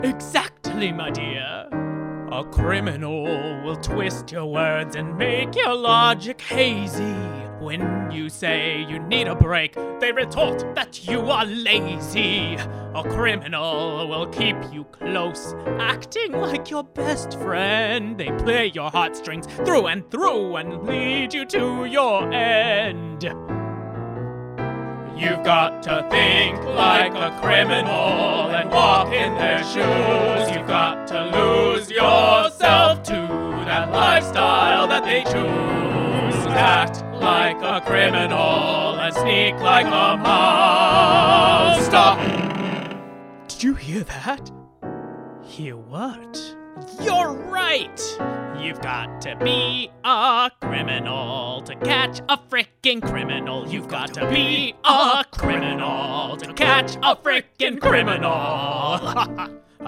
GINGER – Upper Part
ROBERTA – Middle Harmony
AGNES – Lower Harmony